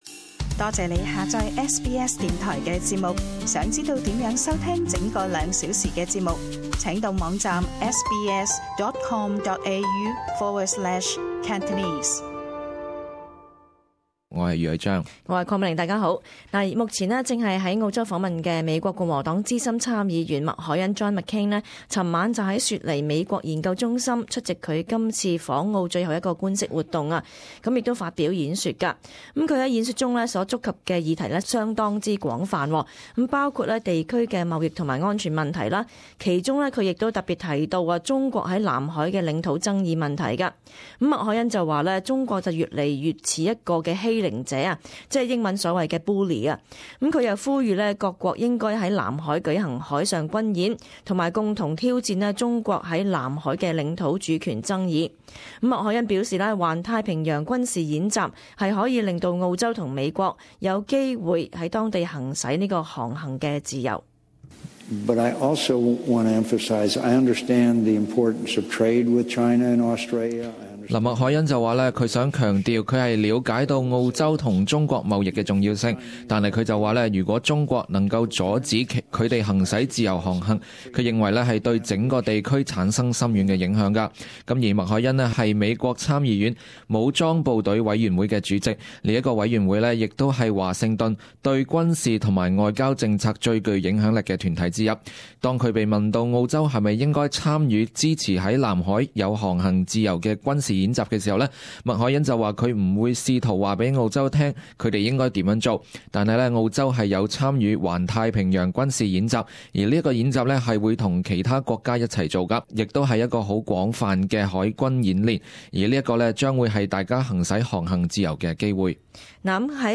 【时事报导】美资深参议员麦凯恩指中国像欺凌恶霸